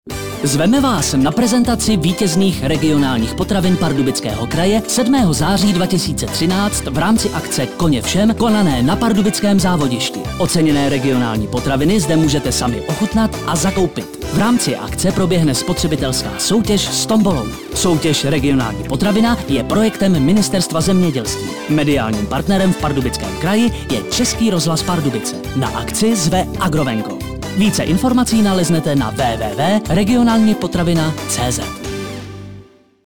Upoutávky v Českém rozhlase Pardubice na ochutnávky  vítězných regionálních potravin v Pardubickém kraji: